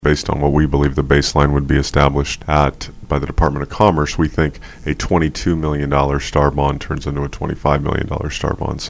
Lewis Motors is looking for another dealership location. City Manager Matt Allen is confident this is a good deal.